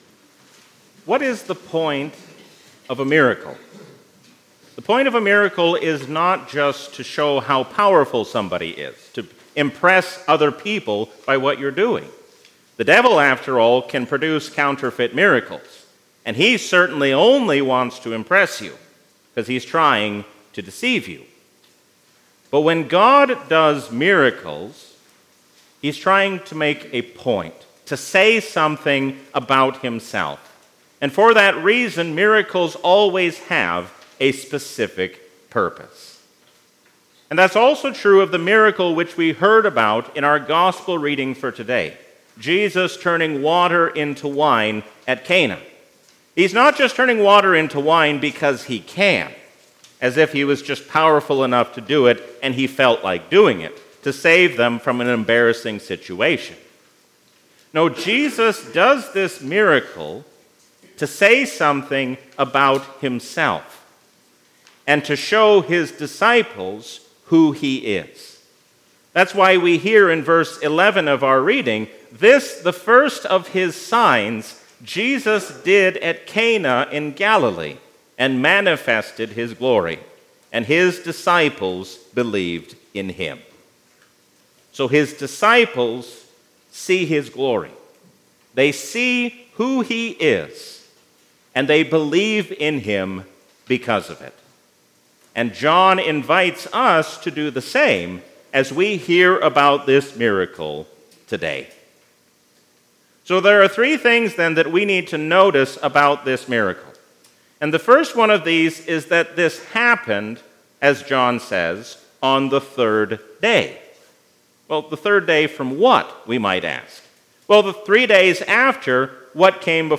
A sermon from the season "Epiphany 2023." God wants us to use His name for good and not for evil.